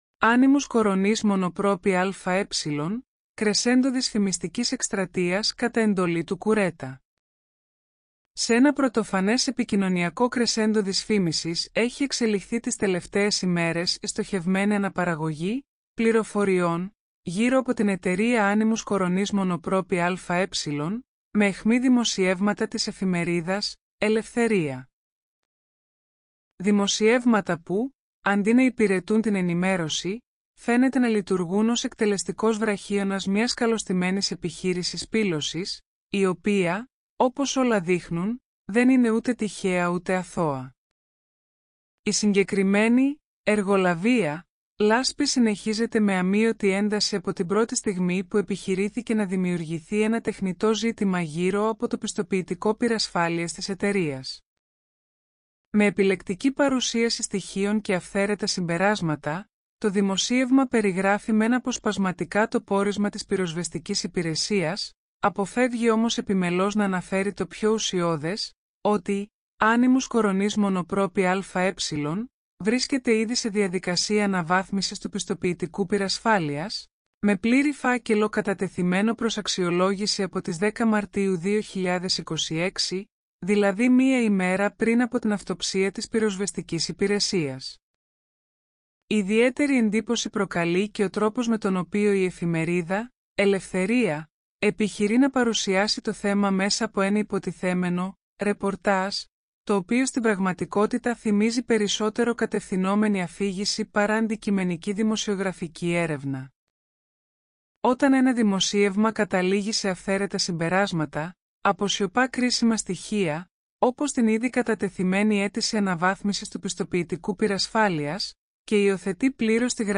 AI υποστηριζόμενο ηχητικό περιεχόμενο